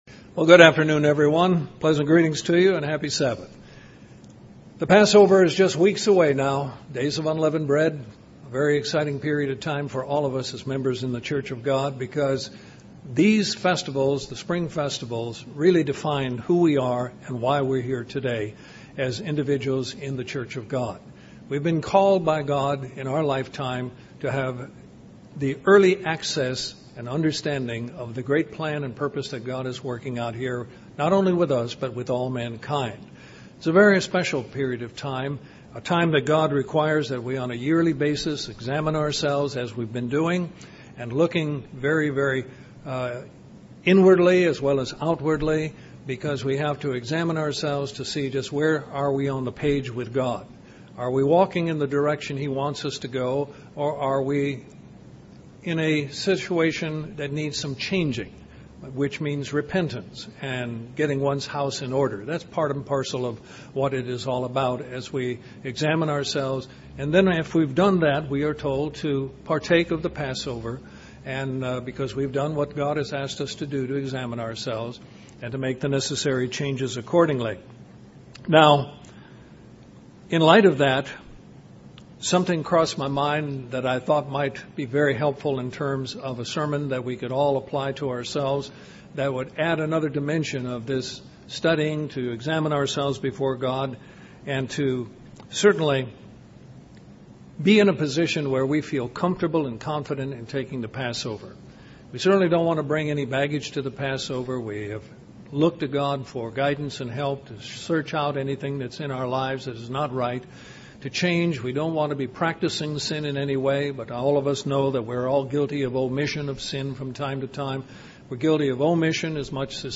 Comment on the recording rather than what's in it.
Given in Columbus, GA Central Georgia